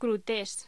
Locución: Crudités
voz